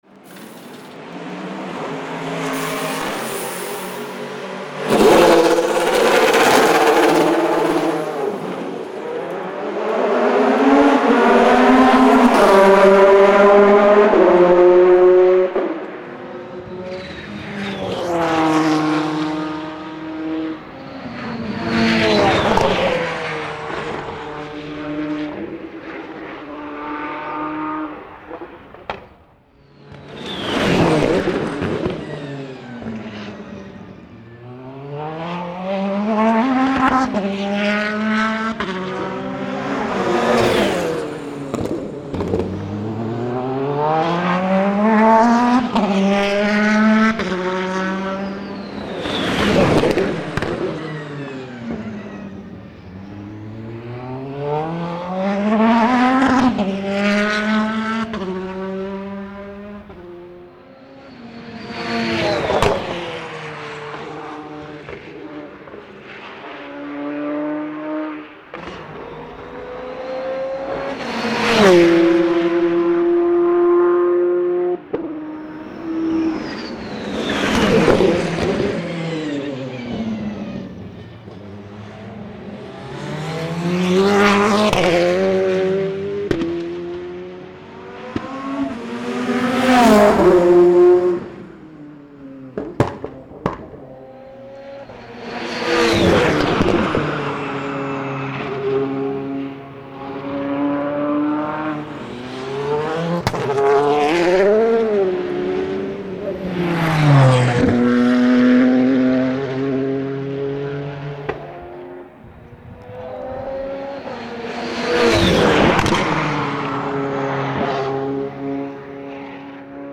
Ecouter le son du moteur: